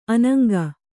♪ anaŋga